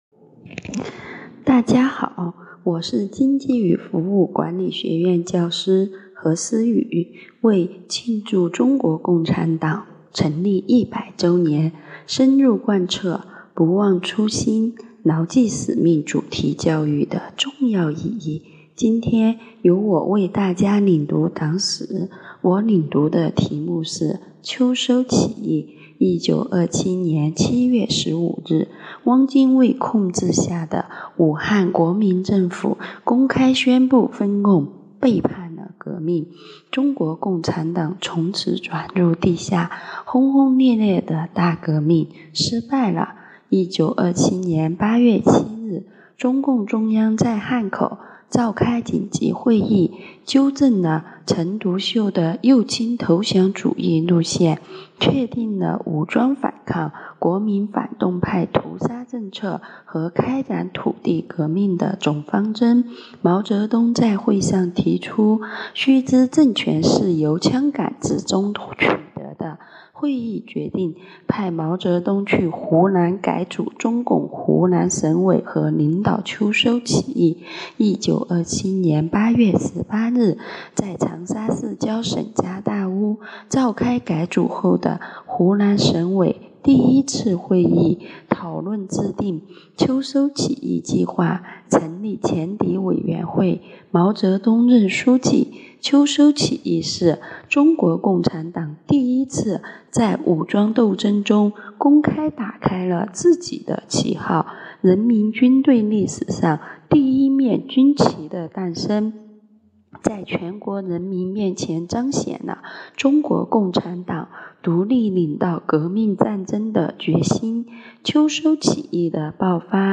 “学党史，献职教，创辉煌” ——教师领读党史系列活动（三）